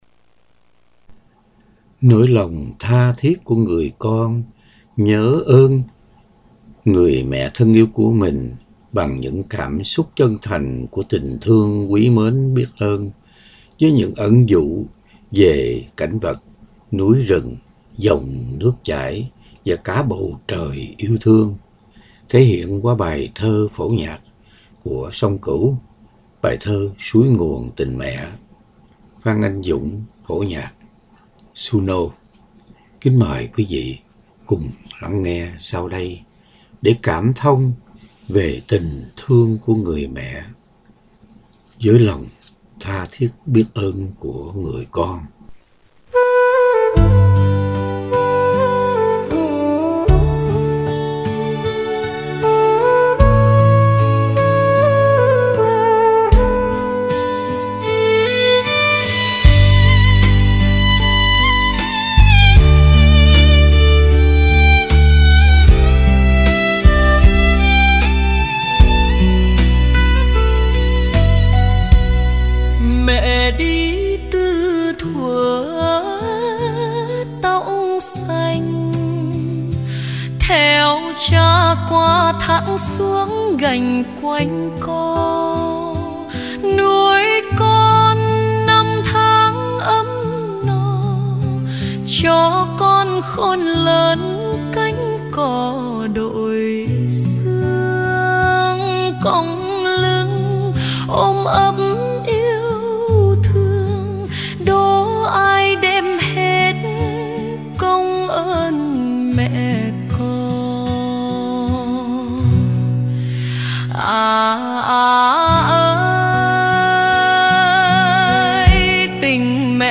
Bài Thơ Phổ Nhạc